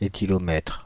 Ääntäminen
Ääntäminen Paris: IPA: [e.ti.lɔ.mɛtʁ] France (Île-de-France): IPA: /e.ti.lɔ.mɛtʁ/ Haettu sana löytyi näillä lähdekielillä: ranska Käännös Substantiivit 1. etilometro {m} Suku: m . Määritelmät Substantiivit Instrument de mesure de la concentration d' alcool dans l'air expiré.